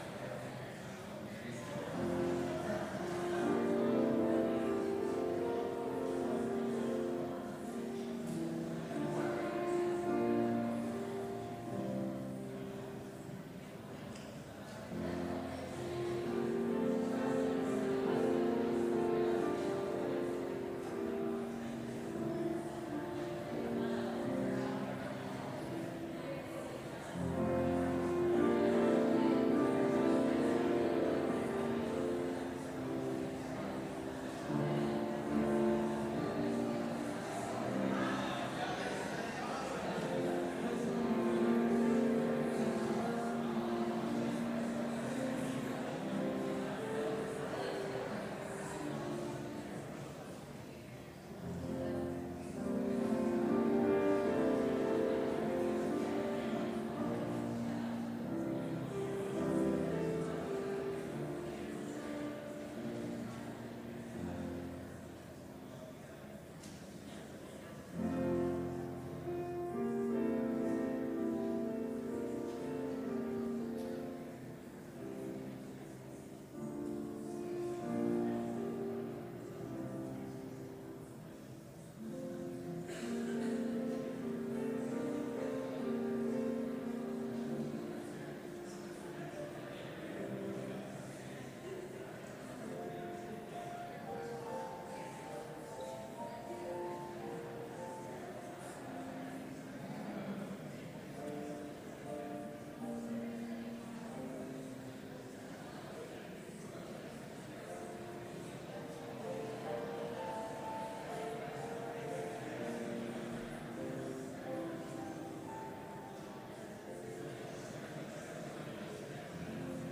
Complete service audio for Chapel - September 20, 2019
Watch Listen Complete Service Audio file: Complete Service Sermon Only Audio file: Sermon Only Order of Service Prelude Shelter me, O God Soloist: Shelter me, O God…